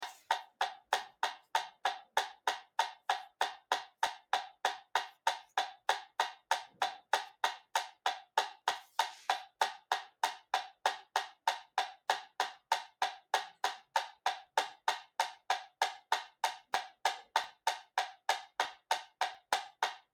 tictac1.mp3